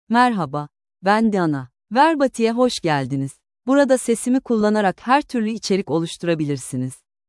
DianaFemale Turkish AI voice
Diana is a female AI voice for Turkish (Turkey).
Voice sample
Listen to Diana's female Turkish voice.
Diana delivers clear pronunciation with authentic Turkey Turkish intonation, making your content sound professionally produced.